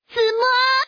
Index of /guizhou_ceshi_lyf/update/1597/res/sfx/woman/